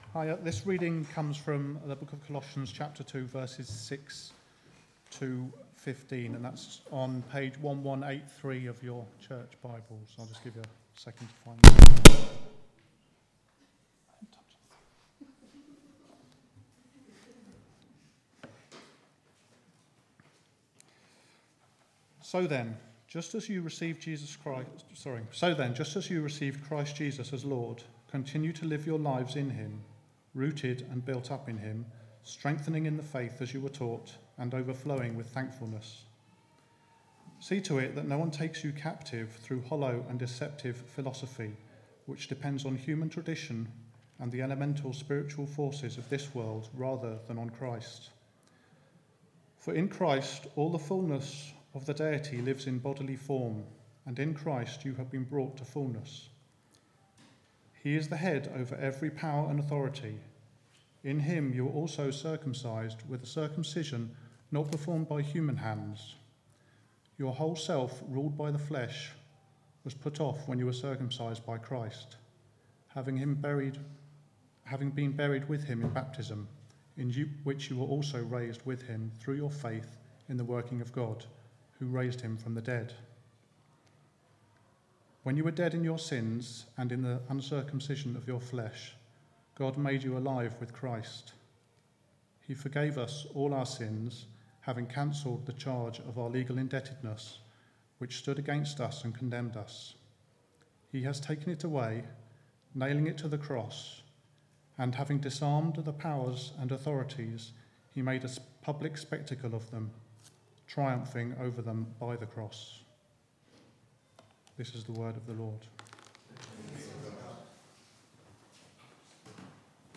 10th-of-october-service-and-reasding.mp3